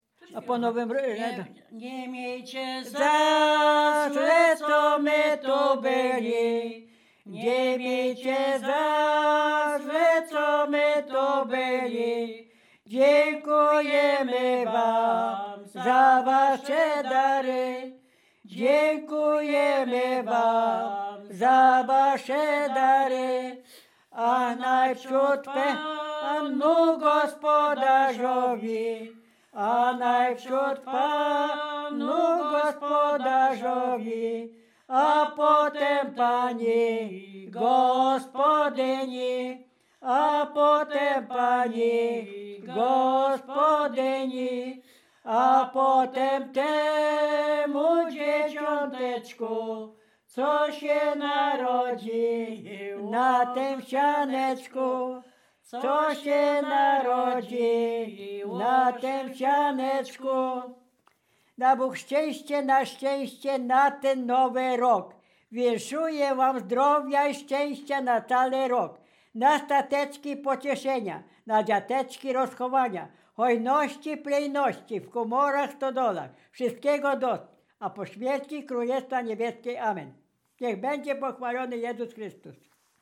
Bukowina Rumuńska
województwo dolnośląskie, powiat lwówecki, gmina Lwówek Śląski, wieś Zbylutów
Kolęda
kolędy życzące zima kolędy kolędowanie gody do gospodarza